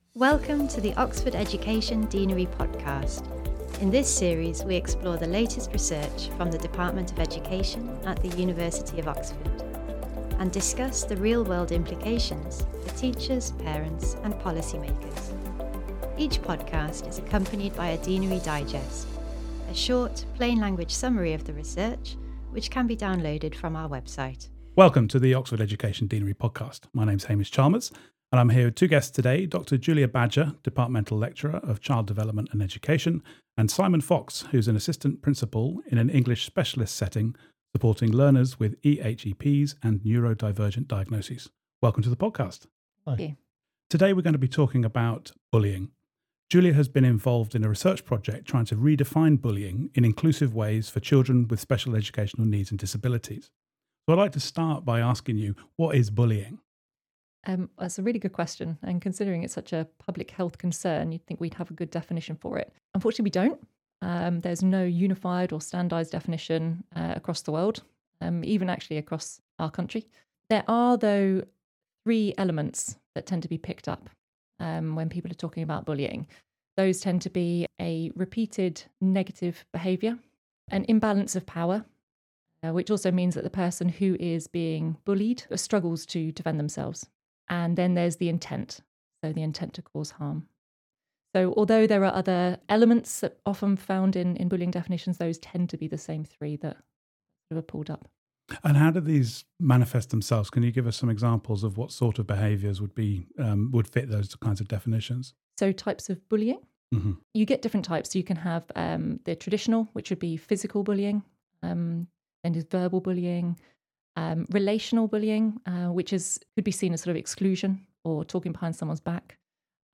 Our conversation explores research that aimed to develop a usable definition of bullying for researchers and school staff that can be applied not only to mainstream school and typically developing children but also to those with SEND, neurodivergence or attending special schools.